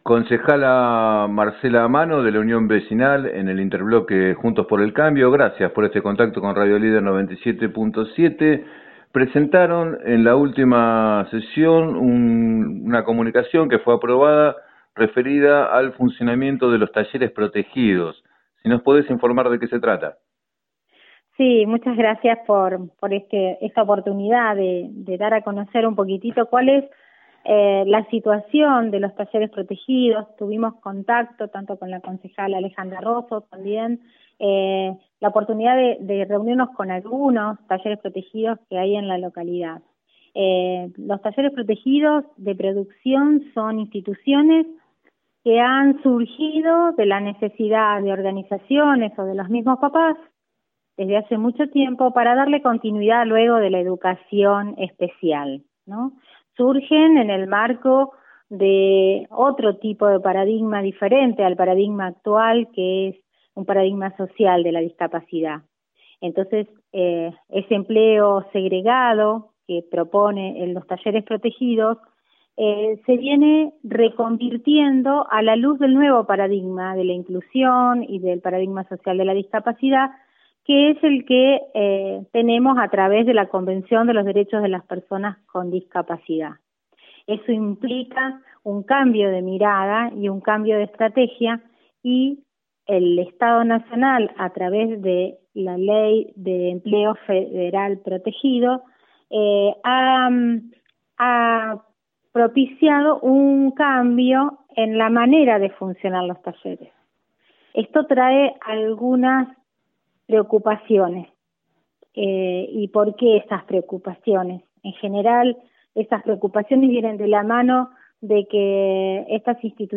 La concejala Marcela Manno, de la Unión Vecinal en Juntos por el Cambio, explicó a Radio Líder 97.7 que los Talleres se vienen reconvirtiendo a la luz del paradigma inclusivo que implica la Ley Federal de Empleo Protegido y que necesitan el acompañamiento del Municipio.